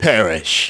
Riheet-Vox_Skill1.wav